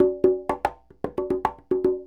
44 Bongo 19.wav